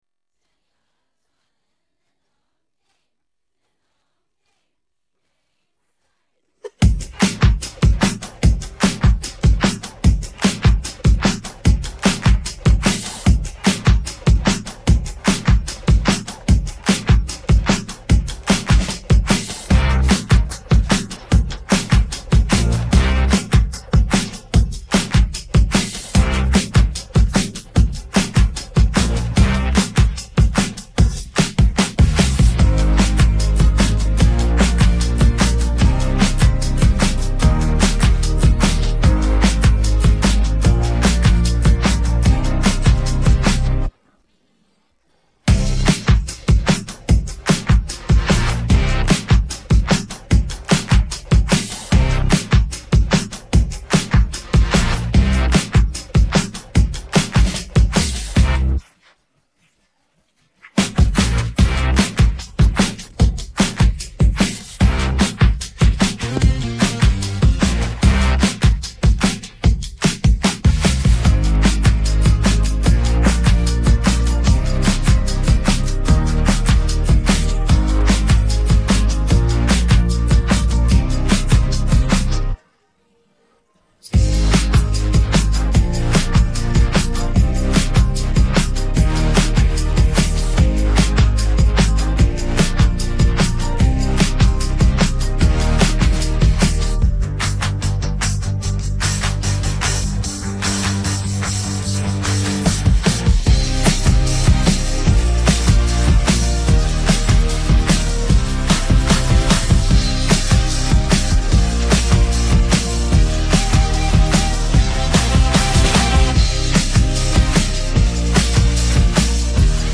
Música de fundo